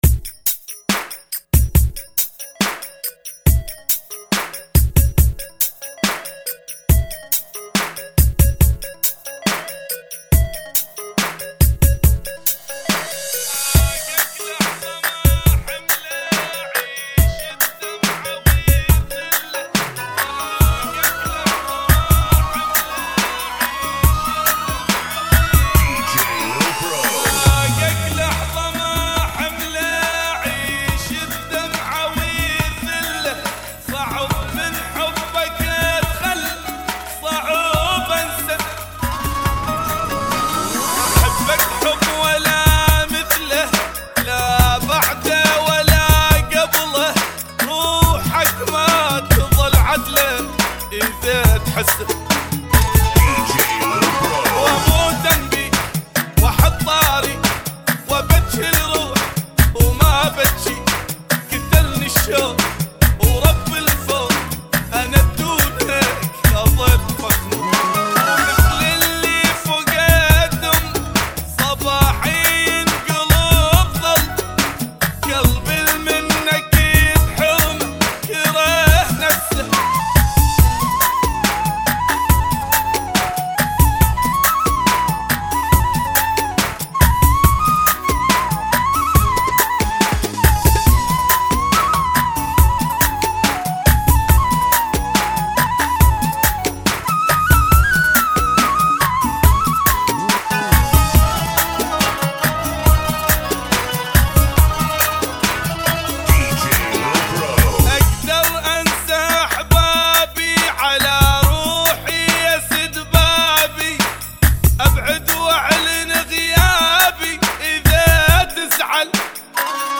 70 bpm